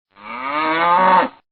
PLAY Cow Mooing
cow-mooing-sound-effect-2-cow-moo-moo-sound-effects.mp3